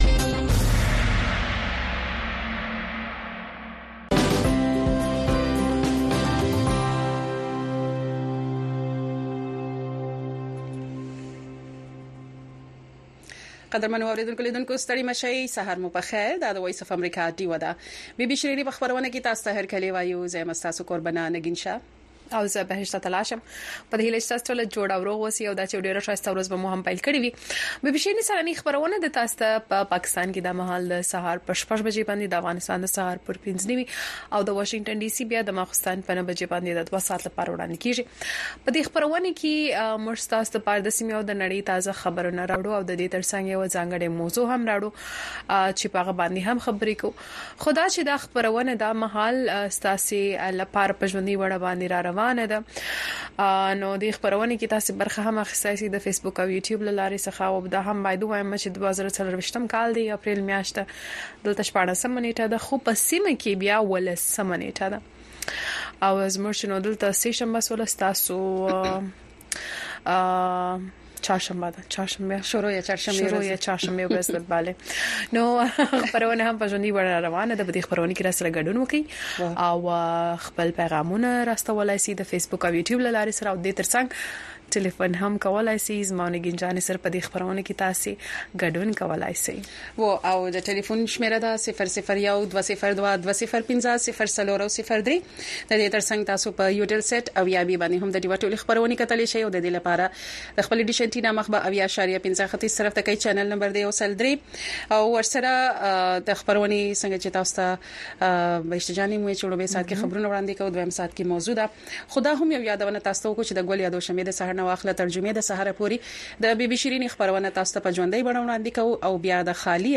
خبرونه
د وی او اې ډيوه راډيو سهرنې خبرونه چالان کړئ اؤ د ورځې د مهمو تازه خبرونو سرليکونه واورئ.